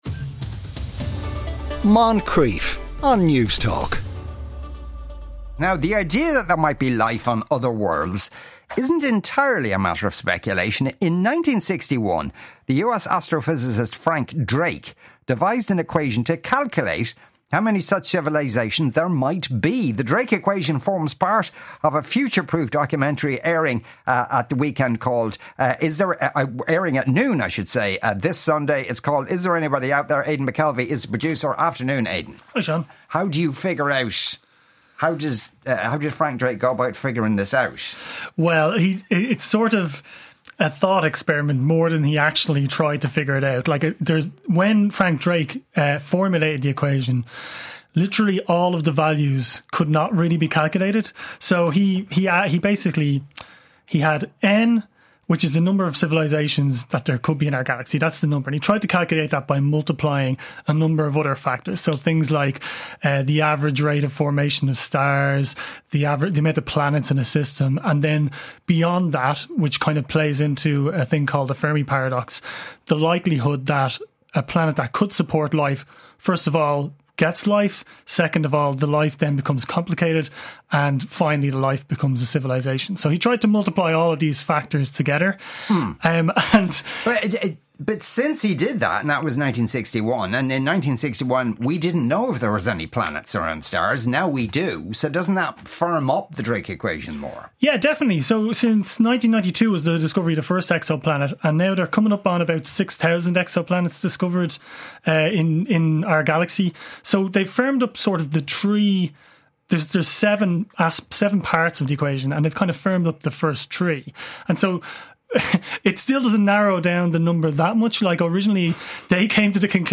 Get all the highlights you missed plus some of the best moments from the show. Watch entertaining calls, Dave Rants, guest interviews, and more!